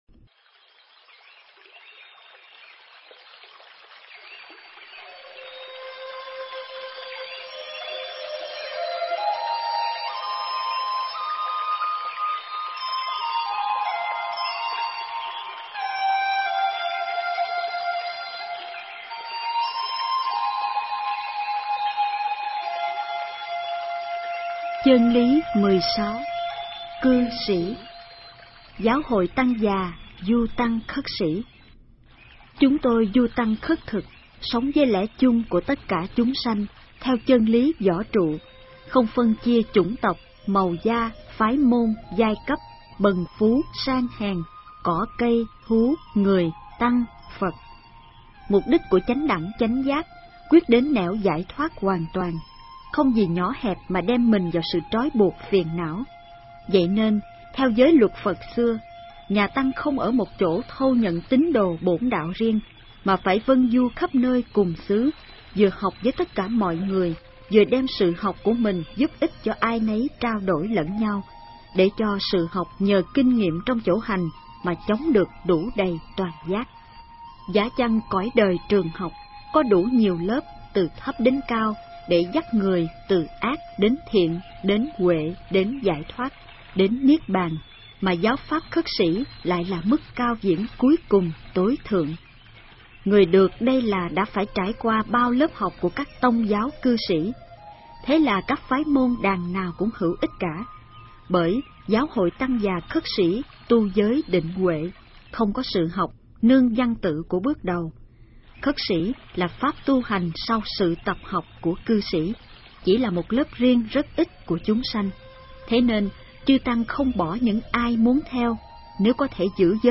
Cư Sĩ (Cư Sĩ Nhật Tụng)Tải xuống chương 16 Nghe sách nói chương 16.